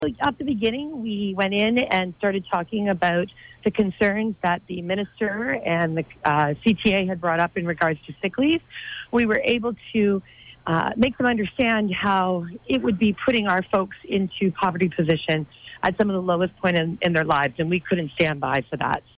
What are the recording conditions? Quinte News Mix 97 Rock 107 CJBQ Green Quinte